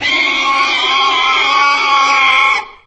boar_anomaly_0.ogg